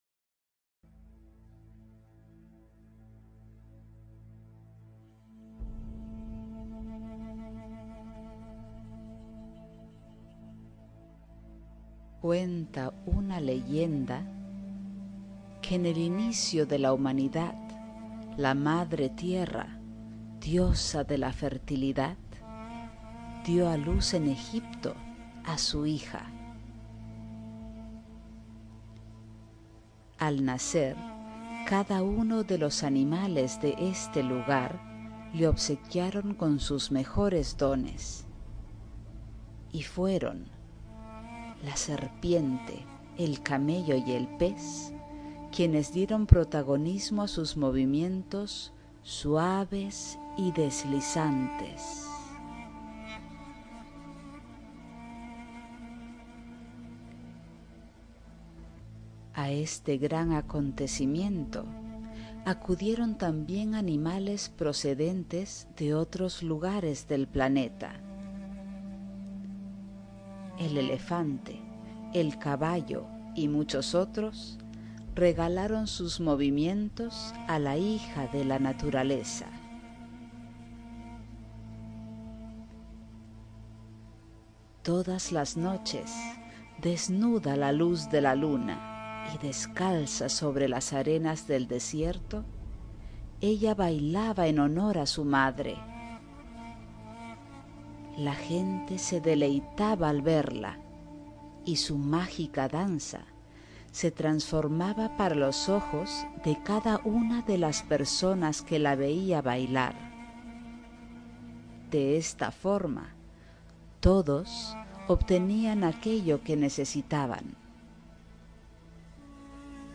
Además de figurar en el documental con mis alumnas y otras profesionales del sector, escribí la leyenda de la danza oriental y la narré con música de fondo para que lo pudieran poner en el documental.